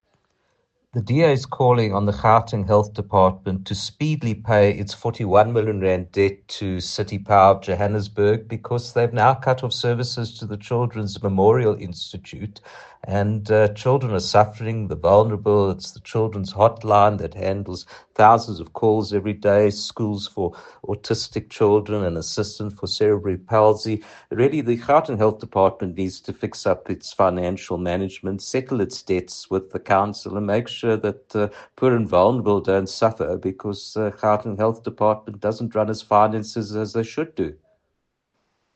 soundbite by Dr Jack Bloom MPL